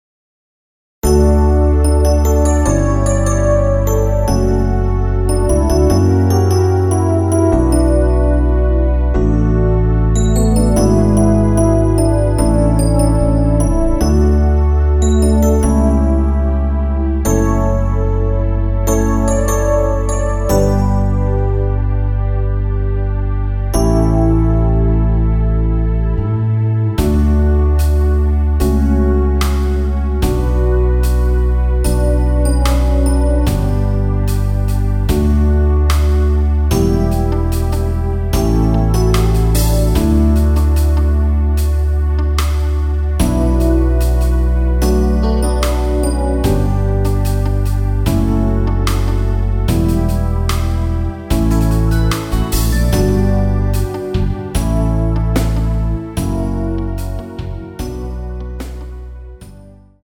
MR입니다.
앞부분30초, 뒷부분30초씩 편집해서 올려 드리고 있습니다.